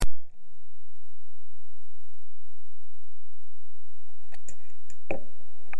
Témoignage enregistré le 25 novembre 2025 à 11h58